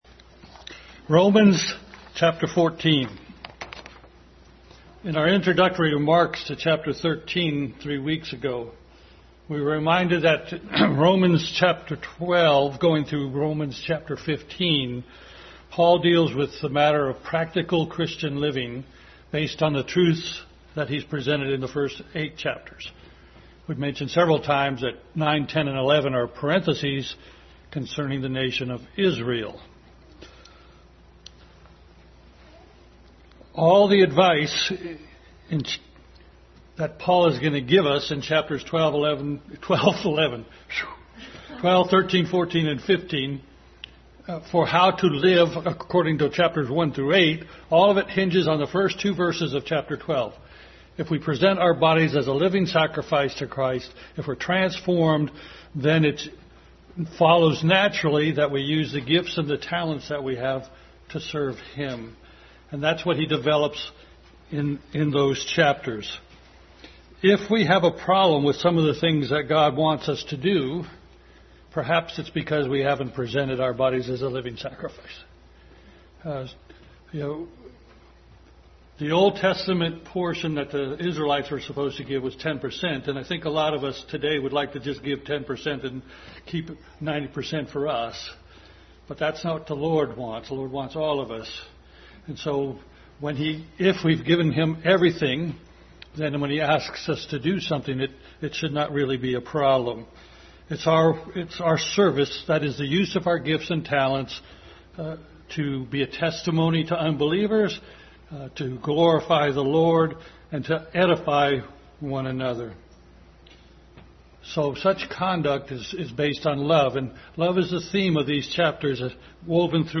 Adult Sunday School continued study in Romans.